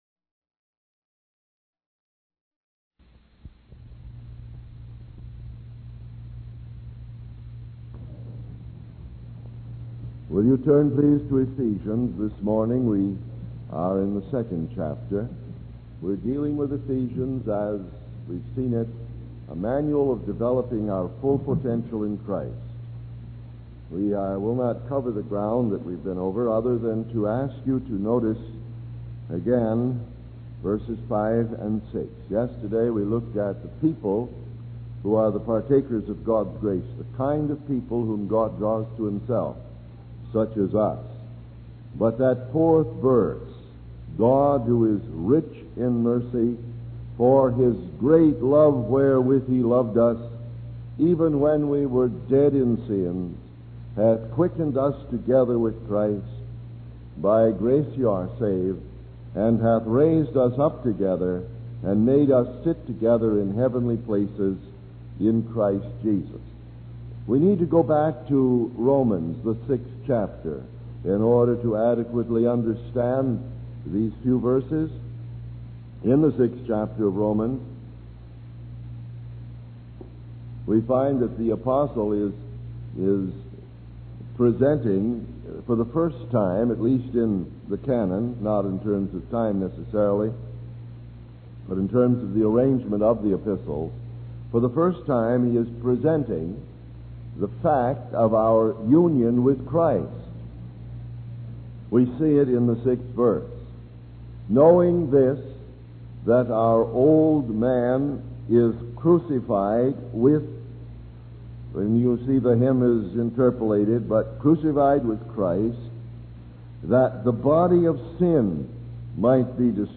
In this sermon, the speaker focuses on the second chapter of Ephesians, which he describes as a manual for developing our full potential in Christ. He emphasizes the importance of understanding our union with Christ and distinguishing between things that differ. The speaker explains that we were not only saved by Christ, but we were also crucified and buried with him, making us wired for victory over sin and temptation.